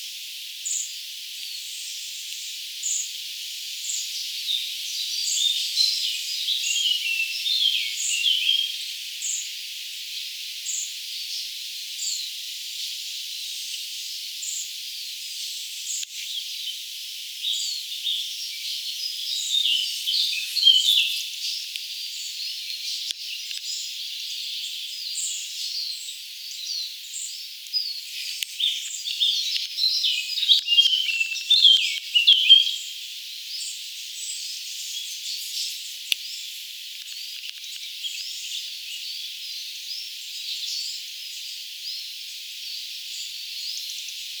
ilmeisesti punakylkirastaan poikanen
Sillä on vähän samankaltainen kerjäysääni
kuin siepoilla, mutta kuitenkin erilainen -
vähän rastasmainen.
ilmeisestikin_punakylkirastaan_poikanen.mp3